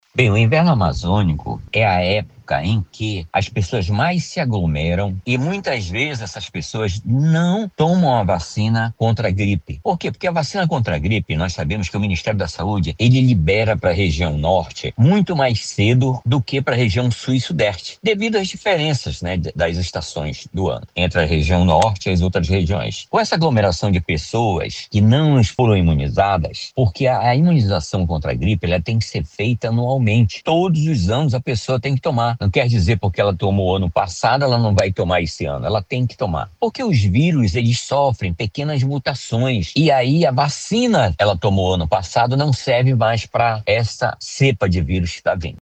SONORA01_MEDICO-.mp3